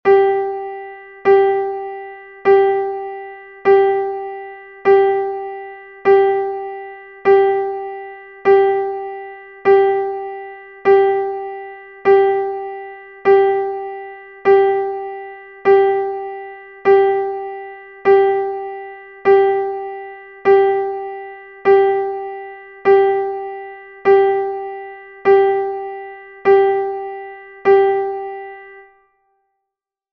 largo.mp3